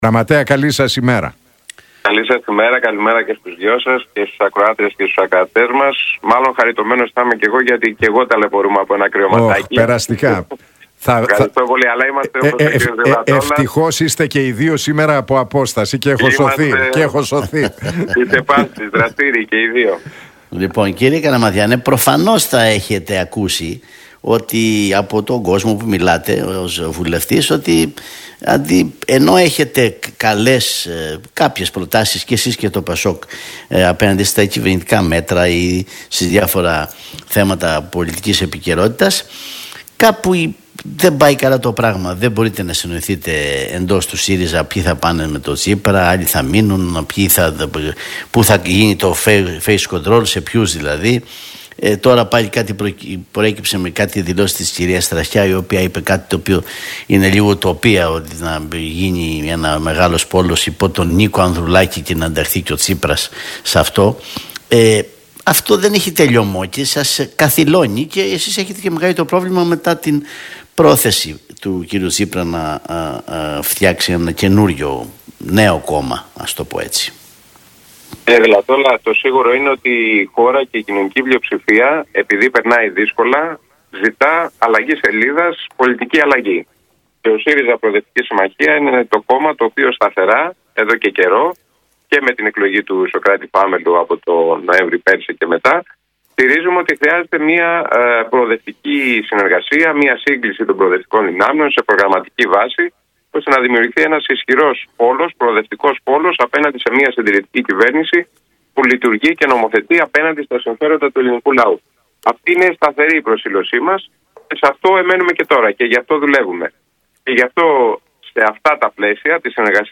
Καλαματιανός στον Realfm 97,8: Όποιος αρνείται τη συνεργασία των προοδευτικών δυνάμεων κρίνεται και από τον ελληνικό λαό — ΔΕΔΟΜΕΝΟ
Για τις συνεργασίες των κομμάτων του προοδευτικού χώρου και τον Αλέξη Τσίπρα μίλησε ο βουλευτής και γραμματέας της Κοινοβουλευτικής Ομάδας του ΣΥΡΙΖΑ-ΠΣ, Διονύσης Καλαματιανός στον Νίκο Χατζηνικολάου